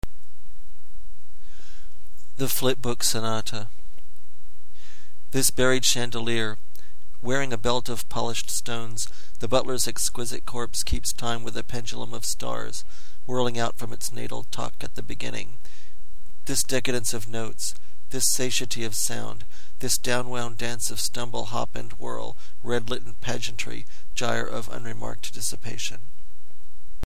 2013 Halloween Poetry Reading